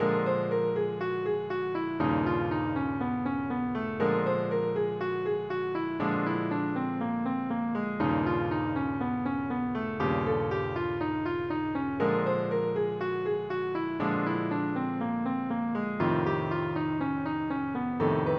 whole tone scale blues changes
blues-changes-whole-tone.mp3